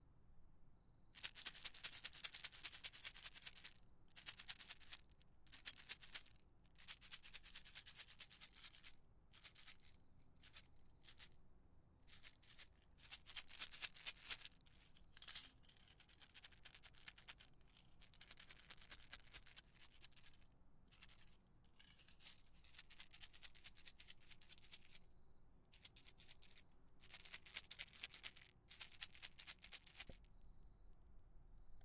描述：木材吱吱作响
Tag: 小溪 嘎嘎作响